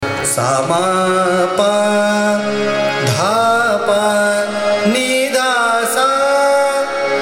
Raga
ArohaS m- P- D P N D S’